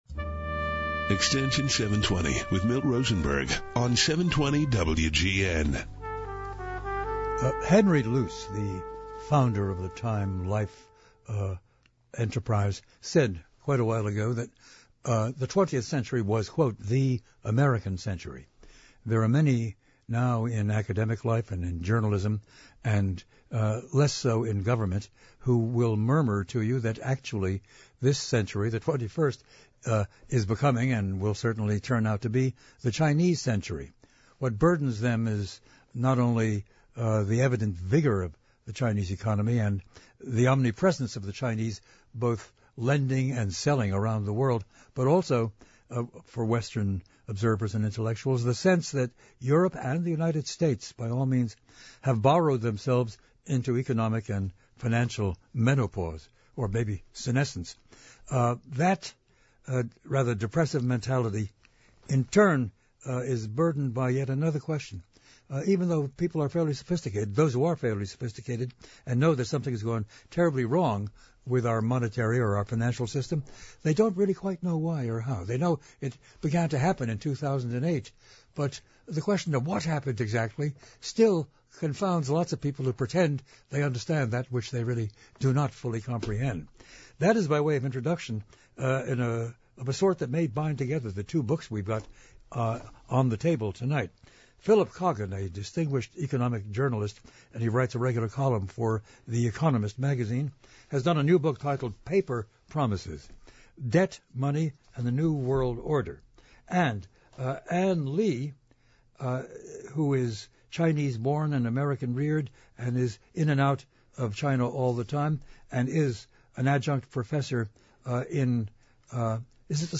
provocative and thought provoking discussion centered on the world of ideas.